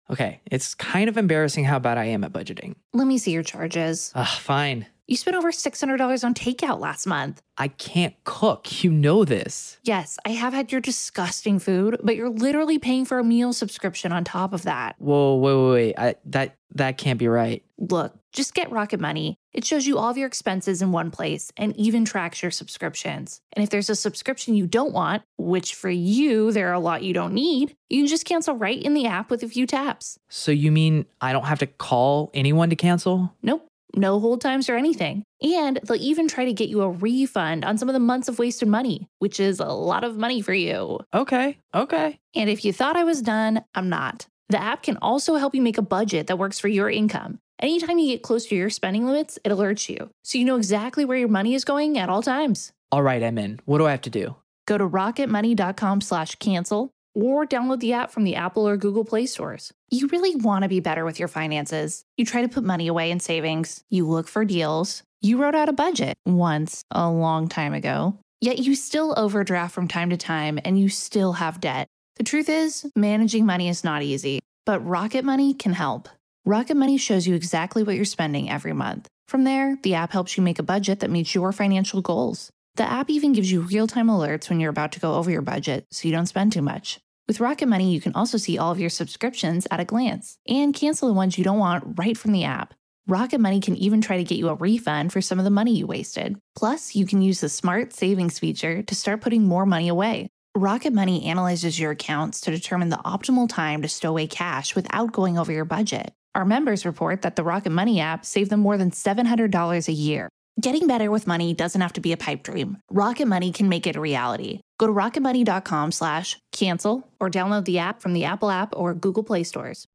Powerful Motivational Speeches Video is an inspiring and self-driven motivational video created and edited by Daily Motivations.